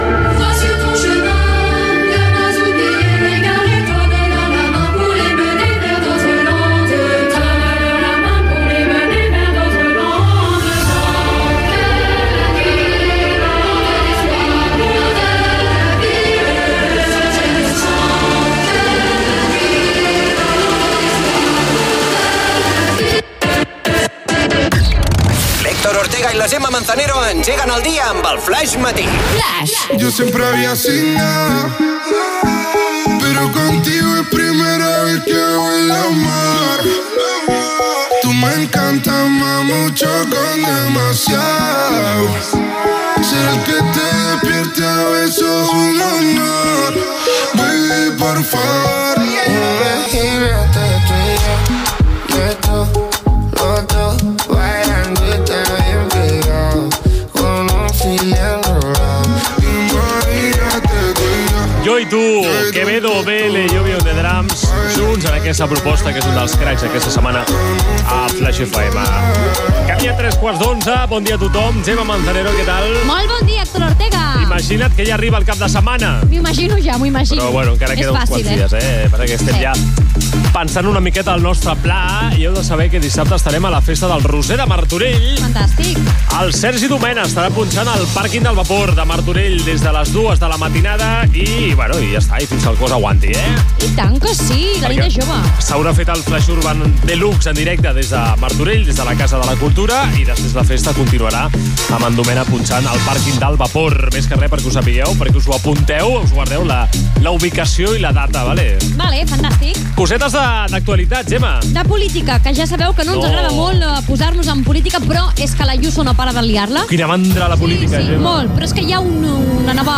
Tema musical, indicatiu, tema musical, hora, activitats que farà Flaix FM al cap de setmana, repàs informatiu a l'actualitat: acusacions de la presidenta de Madrid Isabel Díaz Ayuso al Lehendakari Imanol Pradales, tema musical.
Musical